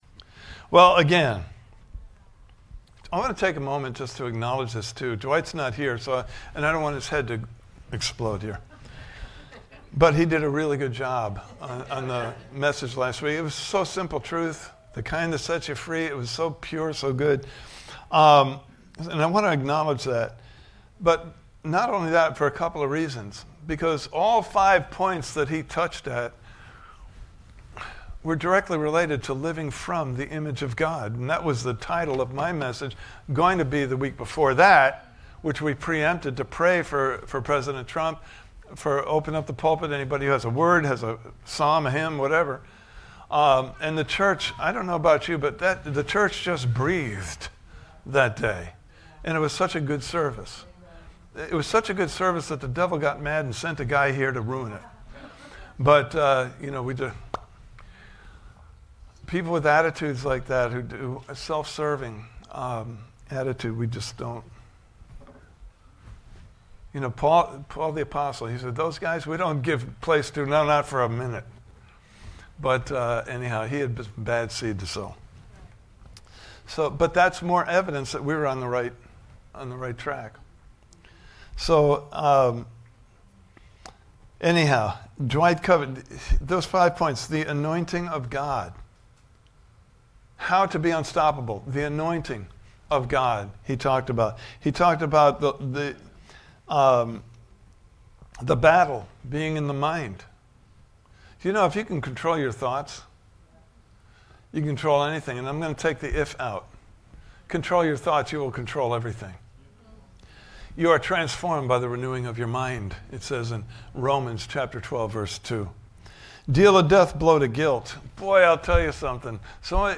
Service Type: Sunday Morning Service « Be Unstoppable!